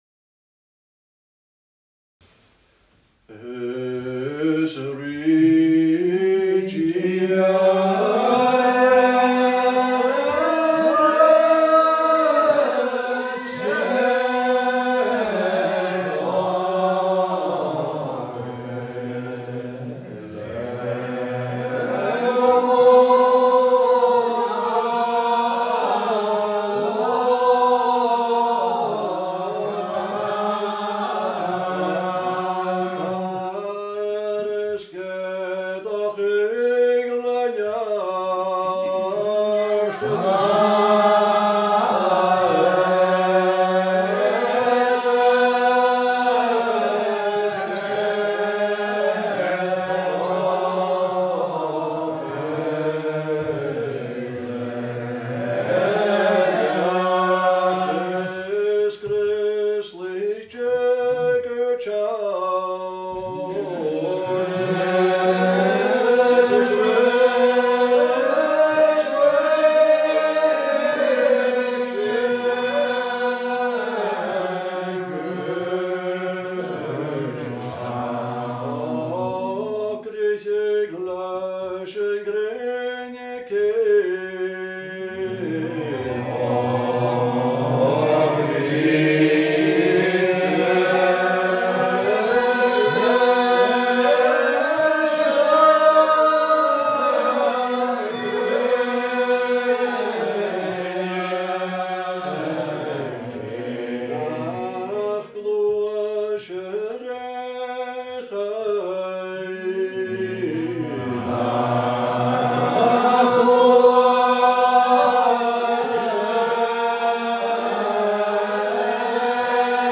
Gaelic Psalmody - class 5
A’ Seinn nan Sailm Gaelic Psalmody